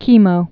(kēmō, kĕmō)